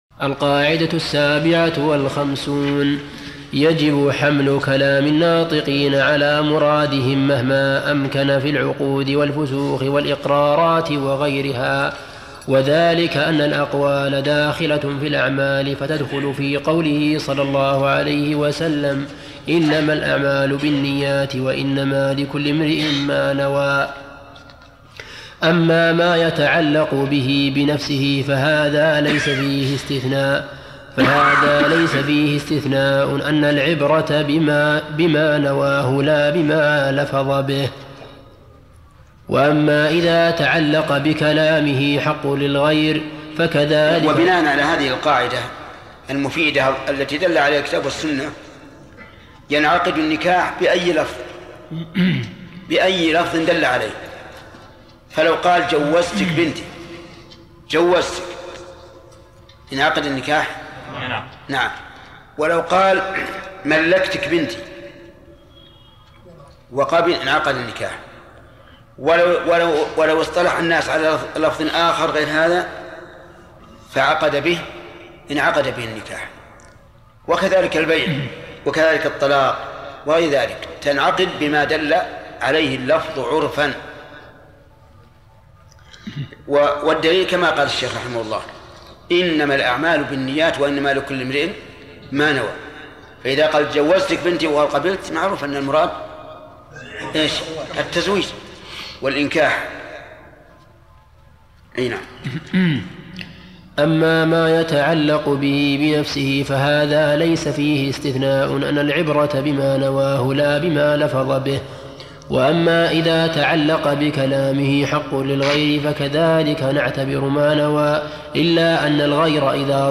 ارسل فتوى عبر "الواتساب" ينبوع الصوتيات الشيخ محمد بن صالح العثيمين فوائد من التعليق على القواعد والأصول الجامعة - شرح الشيخ محمد بن صالح العثيمين المادة 80 - 148 القاعدة 57 يجب حمل كلام الناطقين على مرادهم مهما...